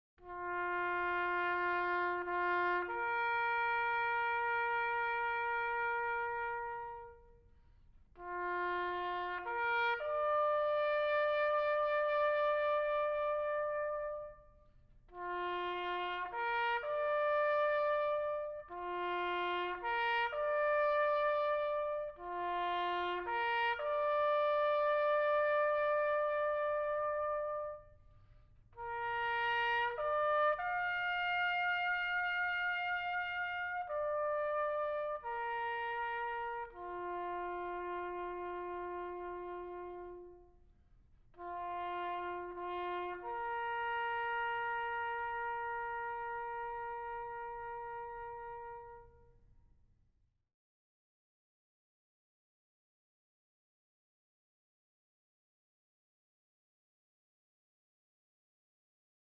taps_uv1.mp3